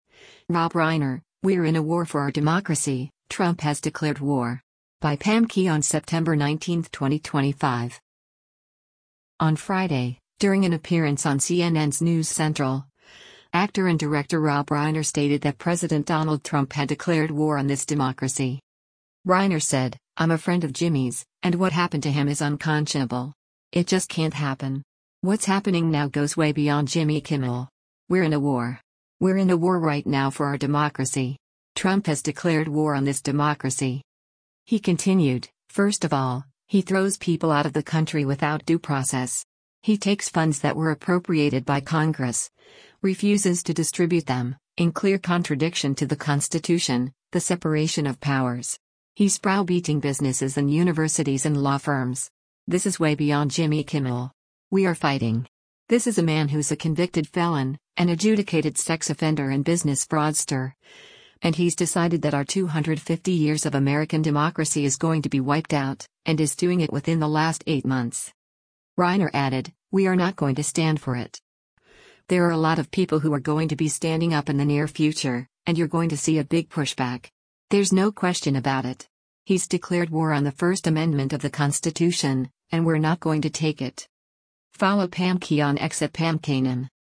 On Friday, during an appearance on CNN’s “NewsCentral,” actor and director Rob Reiner stated that President Donald Trump had “declared war on this democracy.”